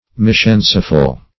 \Mis*chance"ful\